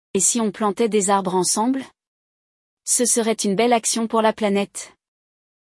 Neste episódio, vamos acompanhar um diálogo entre dois amigos que falam sobre um projeto socioambiental e decidem, juntos, plantar árvores.